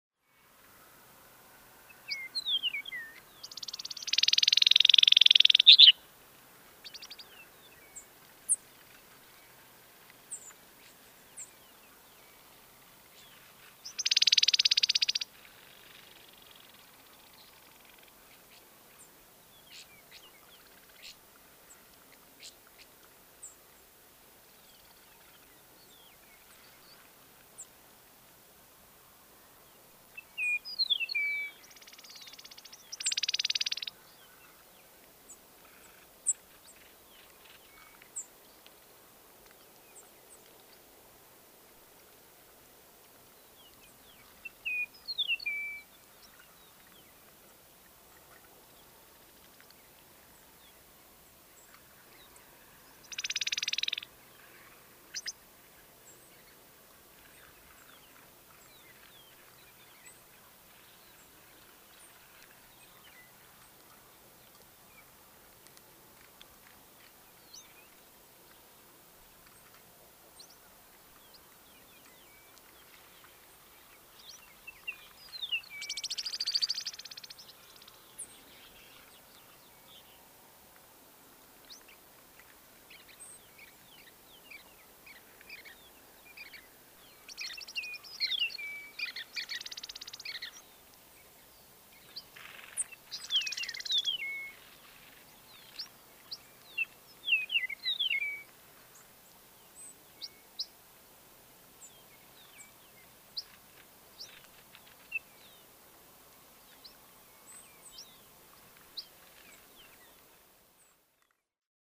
You can also listen for their distinctive calls and song, and locate them that way.
Chihuahuan Meadowlark Vocalizations
Chihuahuan-Meadowlark-3.mp3